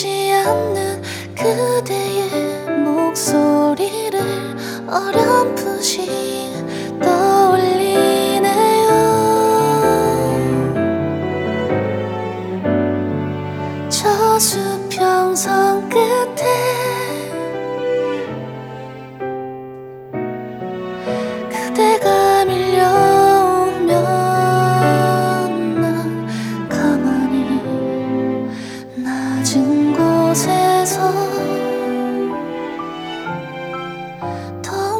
# Korean Indie